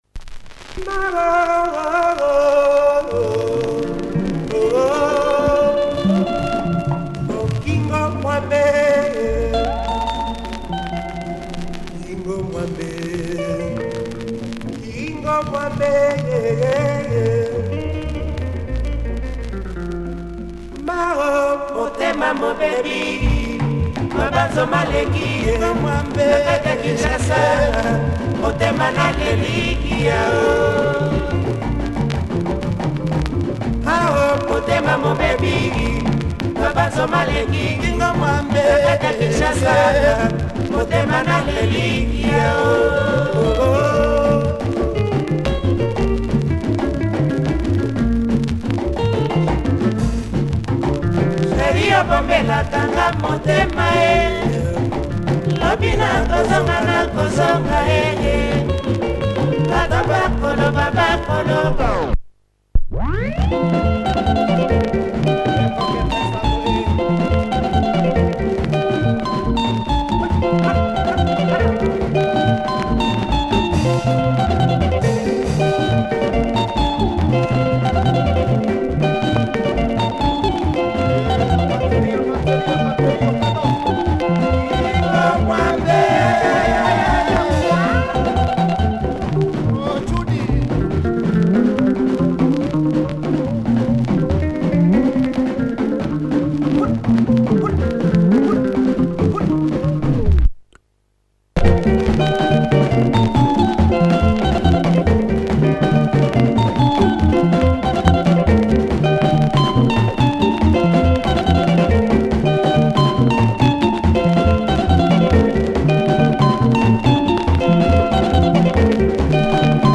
Nice and percussive, nice Lingala with sweet arrangements.